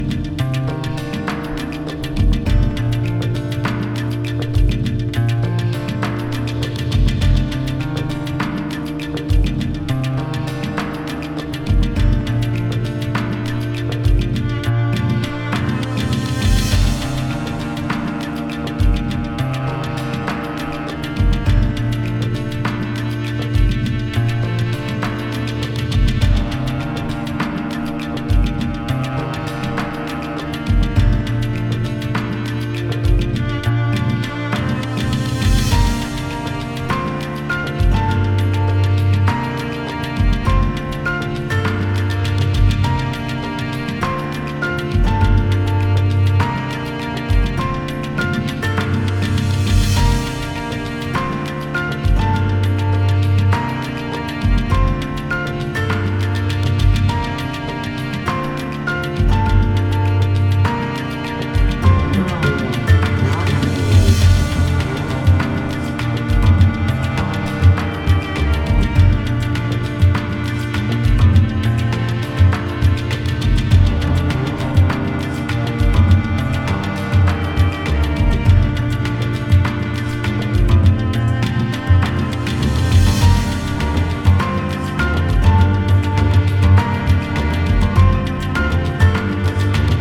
両面この盤のみのクラブ仕様バージョン。
DEEP HOUSE / EARLY HOUSE# HEADZ / ELECTRONICA / CHILOUT